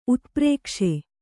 ♪ utprēkṣe